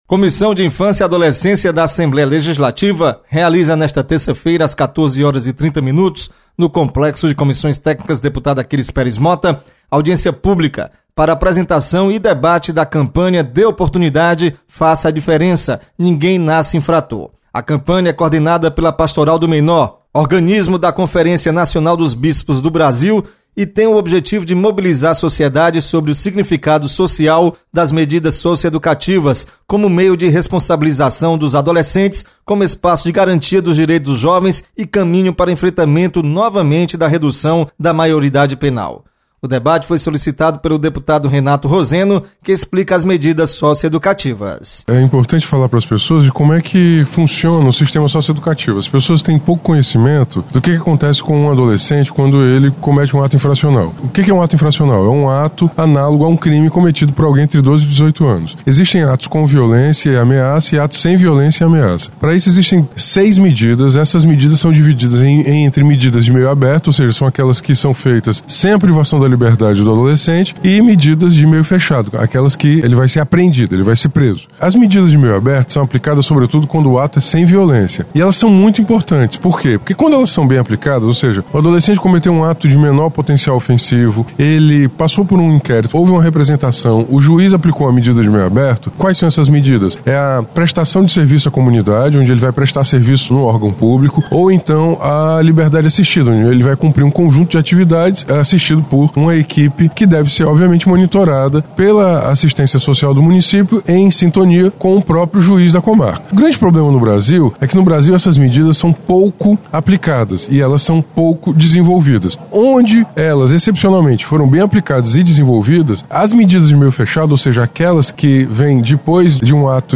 Comissão da Infância e Adolescência realiza reunião nesta terça-feira. Repórter